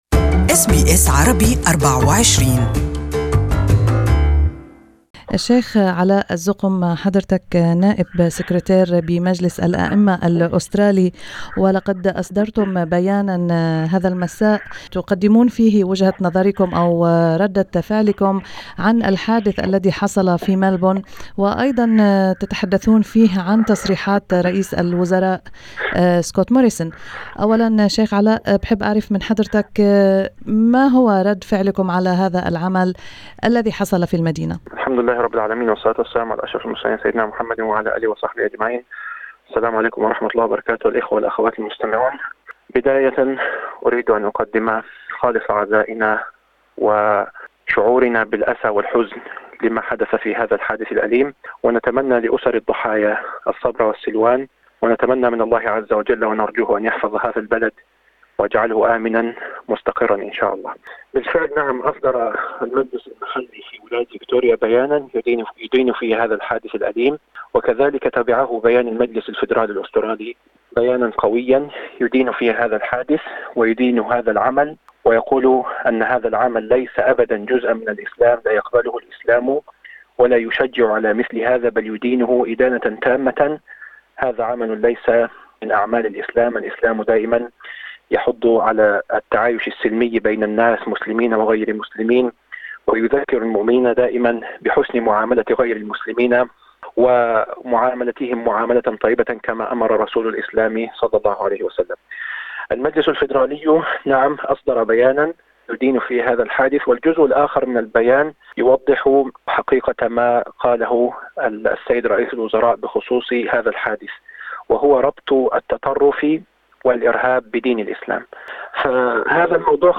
This interview